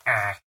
MinecraftConsoles / Minecraft.Client / Windows64Media / Sound / Minecraft / mob / villager / no2.ogg
should be correct audio levels.